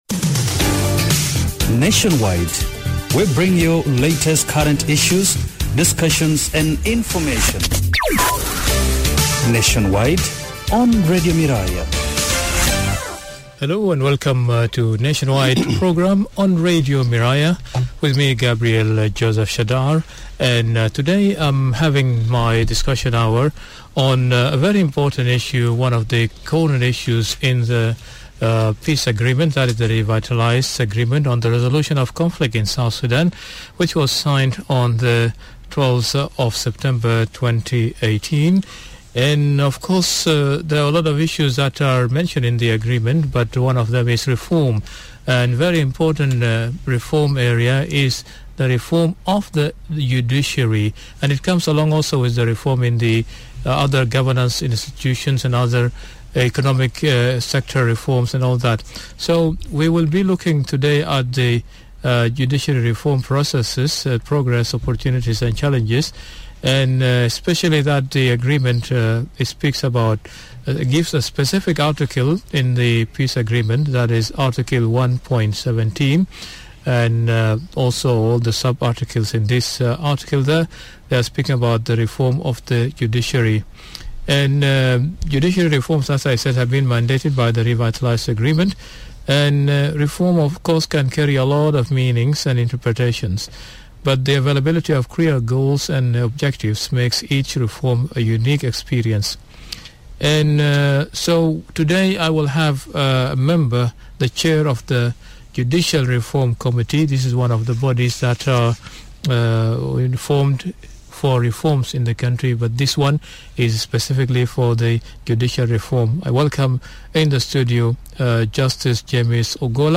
Listen to the full interview as he gives context to the unique situation facing South Sudan.